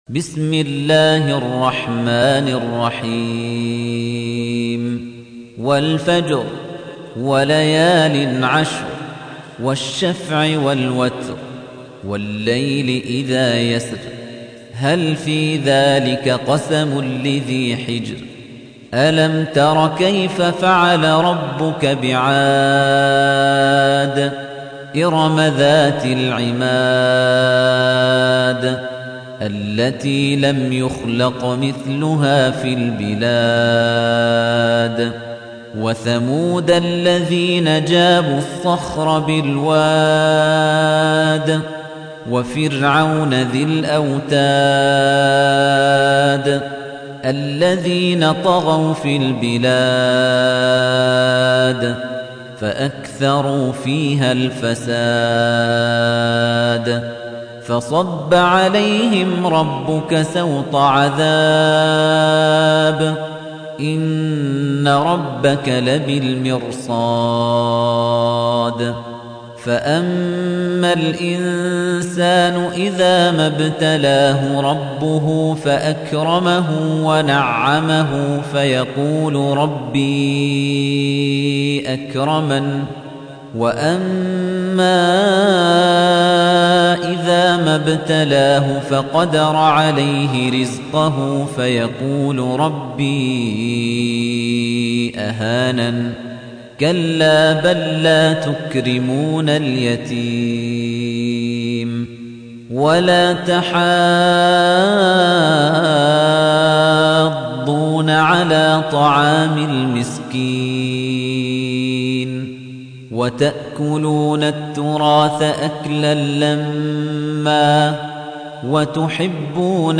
تحميل : 89. سورة الفجر / القارئ خليفة الطنيجي / القرآن الكريم / موقع يا حسين